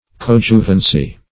Coadjuvancy \Co*ad"ju*van*cy\, n. Joint help; cooperation.